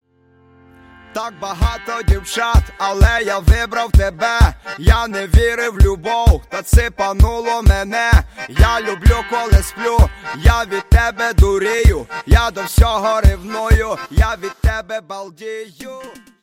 • Качество: 128, Stereo
позитивные
веселые